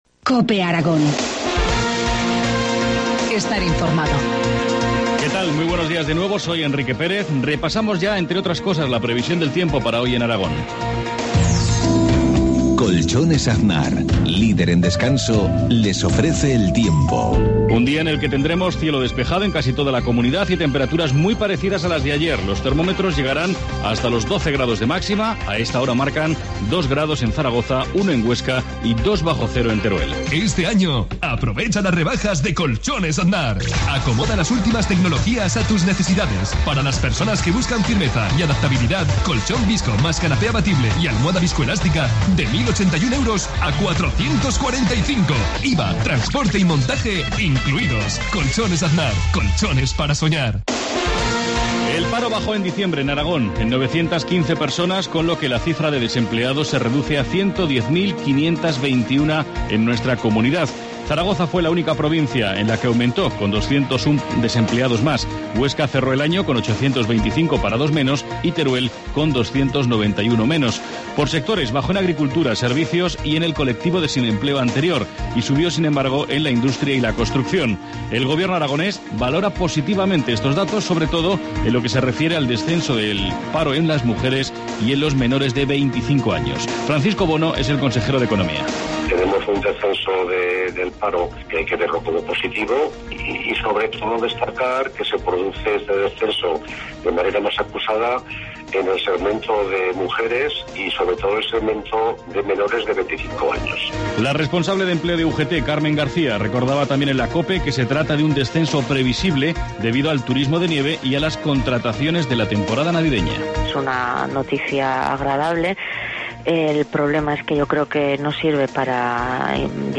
Informativo matinal, viernes 4 enero, 7,53 horas